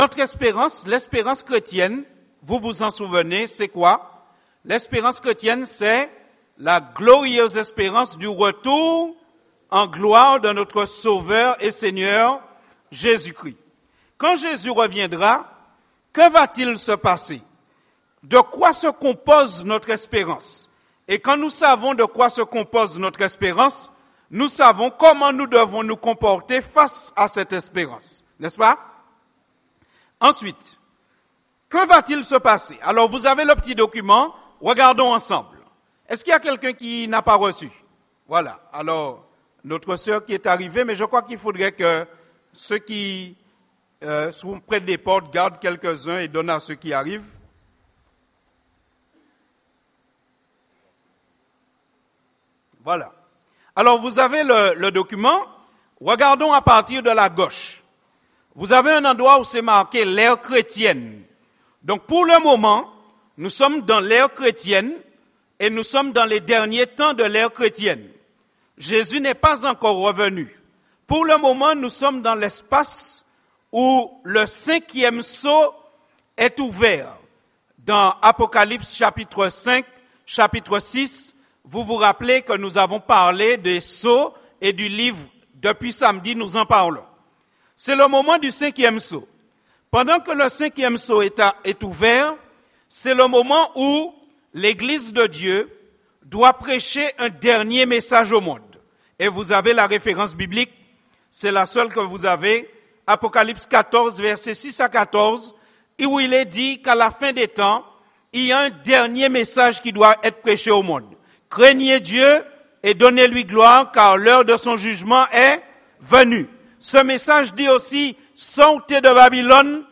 Sermons Semaine de prière